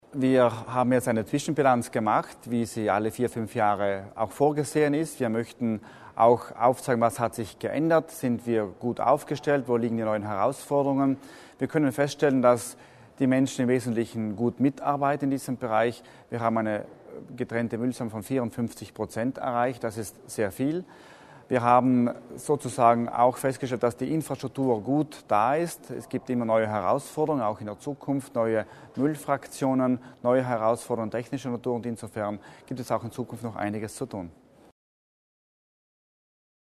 Landesrat Laimer zur Abfallwirtschaft